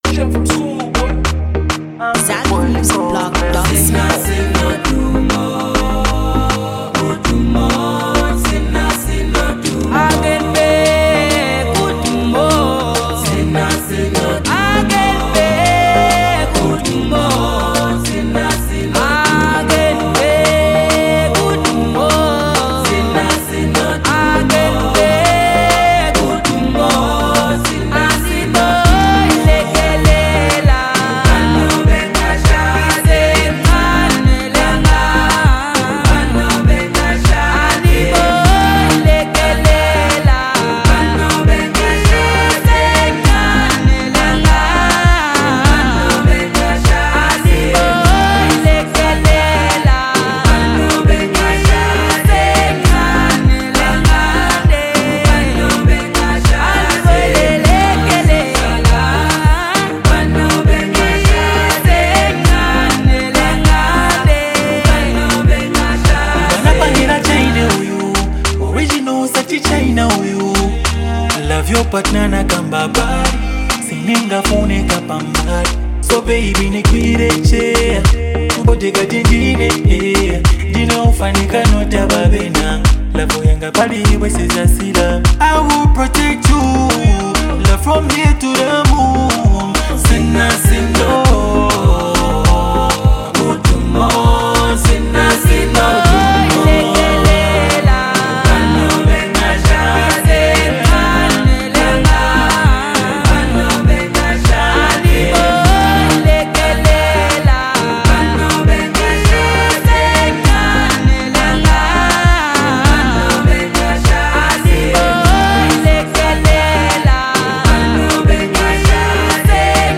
South African group
emotionally resonant track
harmonious backing